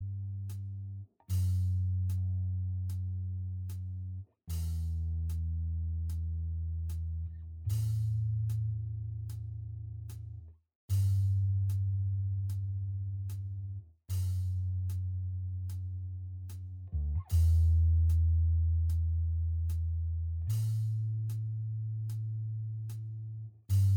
Minus Lead And Solo Indie / Alternative 7:23 Buy £1.50